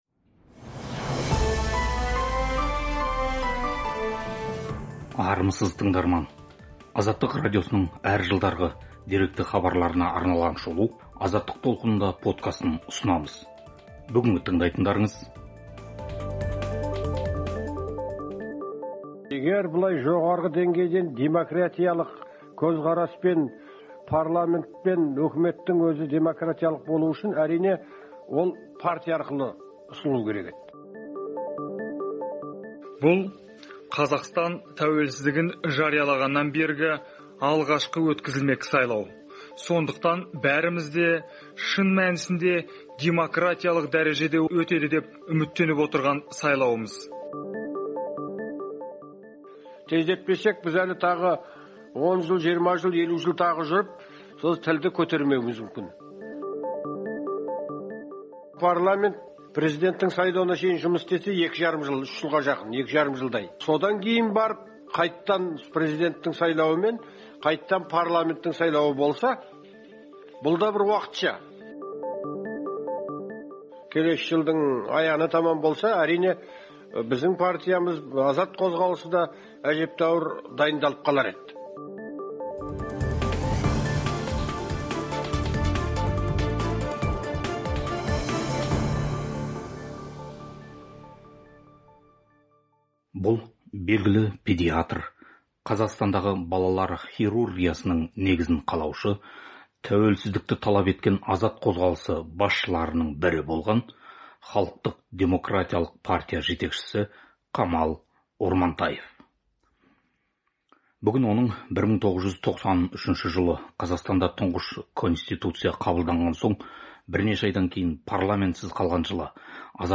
"Азаттық толқынында" подкасында дәрігер, ғалым Камал Ормантаевтың Халықтық-демократиялық партия төрағасы кезінде радиоға берген сұхбатына шолу жасаймыз. Сұхбатта Конституциялық сот таратқан он үшінші шақырылымдағы Жоғарғы кеңес сайлауы алдындағы саяси ахуал баяндалған.